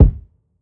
Sample Kick.wav